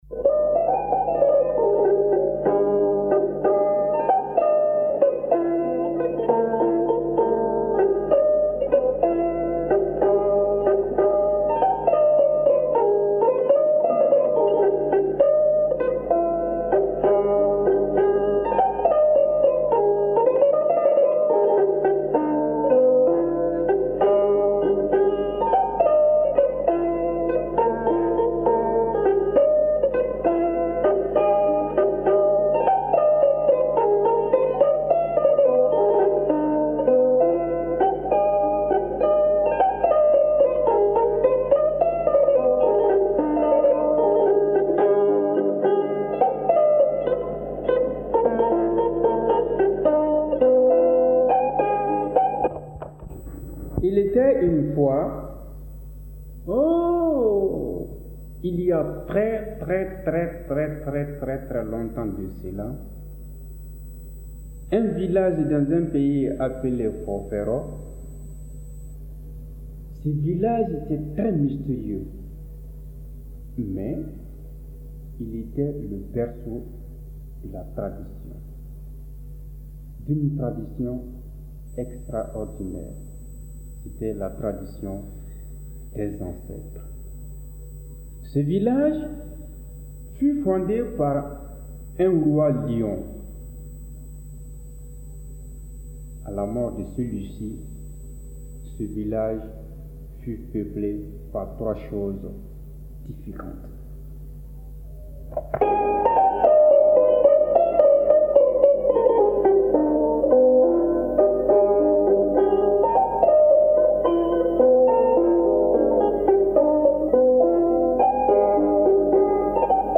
Une cérémonie de pêche : Conte malien